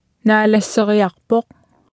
Below you can try out the text-to-speech system Martha.
Speech Synthesis Martha